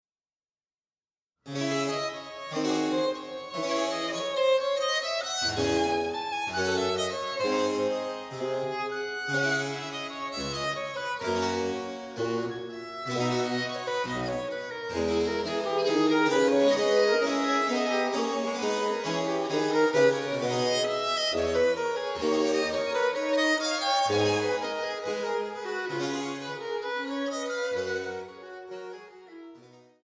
für zwei Violinen